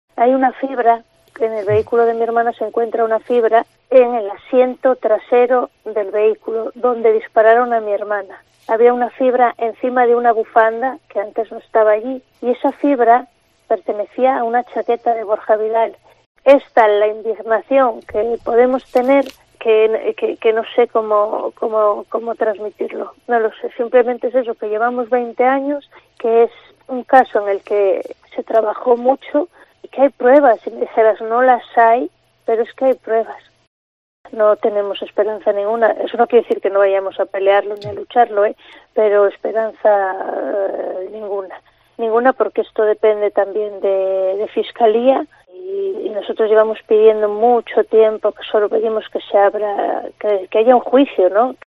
Declaraciones en COPE